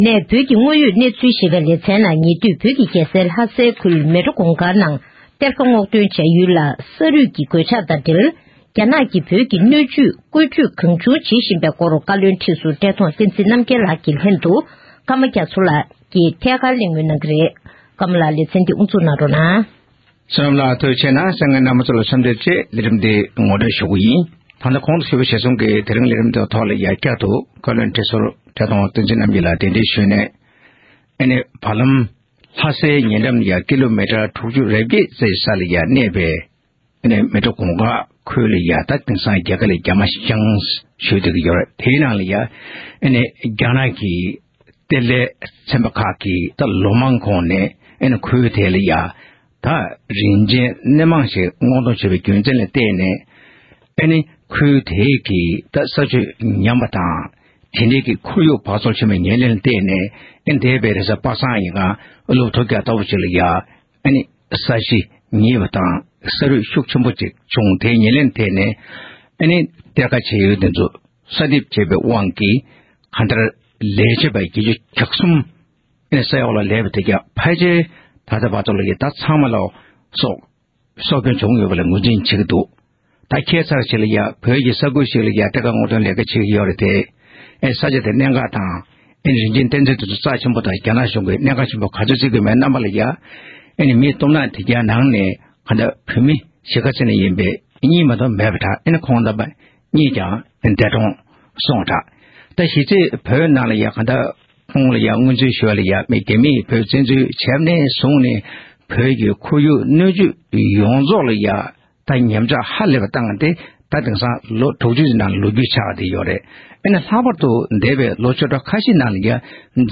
གླེང་མོལ་གནང་བར་གསན་རོགས༎